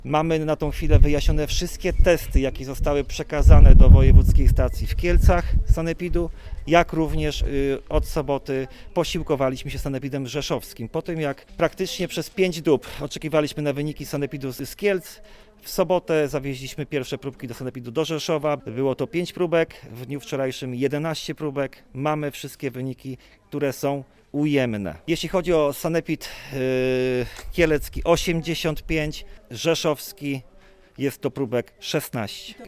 Mówi starosta sandomierski Marcin Piwnik: